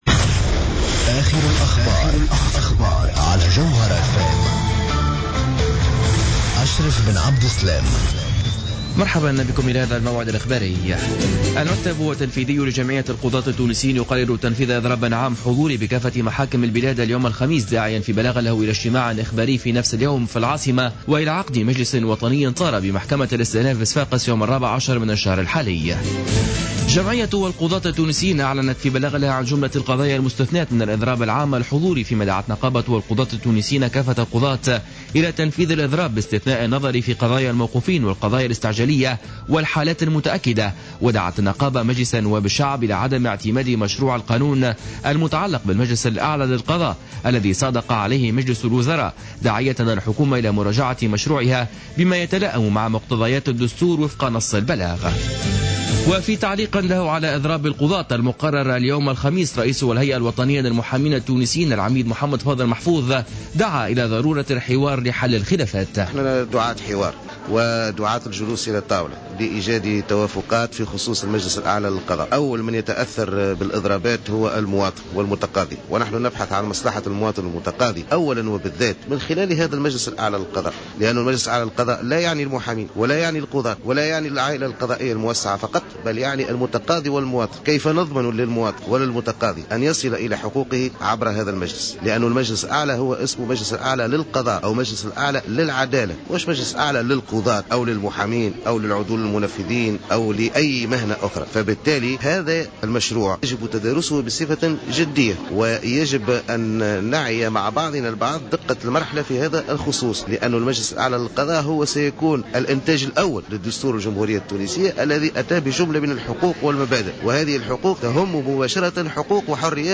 نشرة أخبار منتصف الليل ليوم الخميس 12 مارس 2015